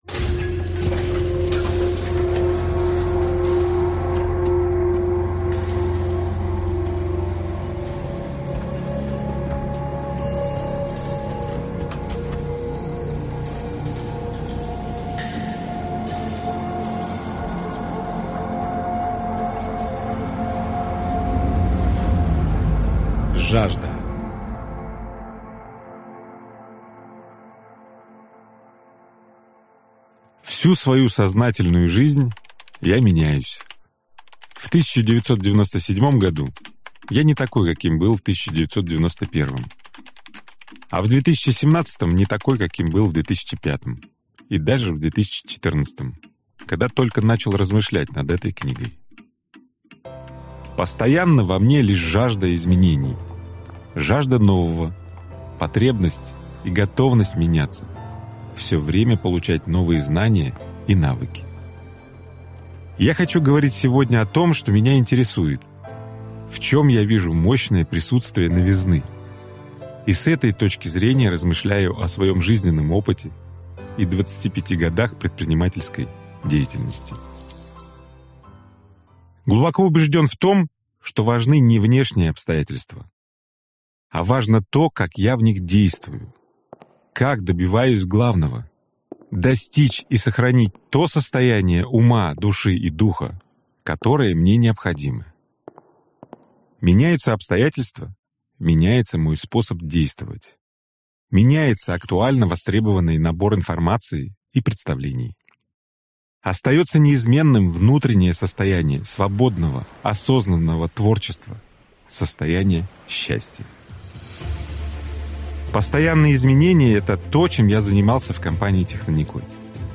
Аудиокнига Жажда | Библиотека аудиокниг